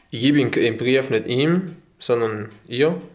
- dialetto tirolese di Salorno nella Bassa Atesina (2016);